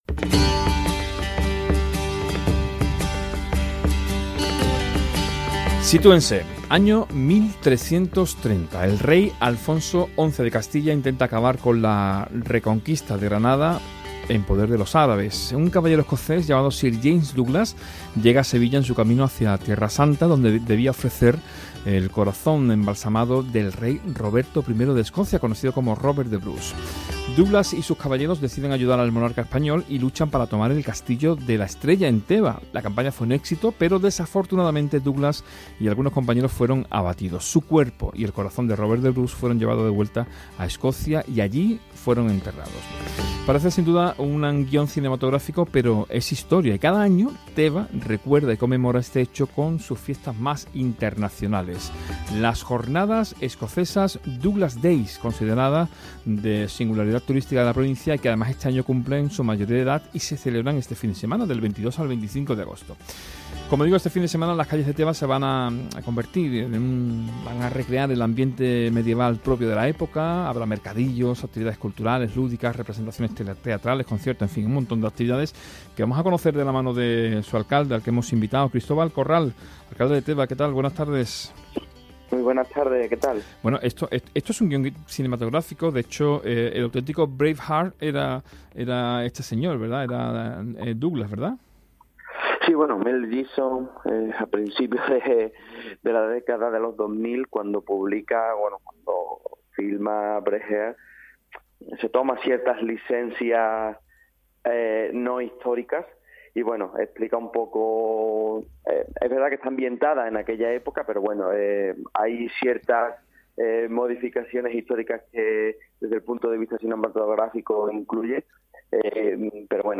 ENTREVISTA | Cristóbal Corral (Alcalde de Teba)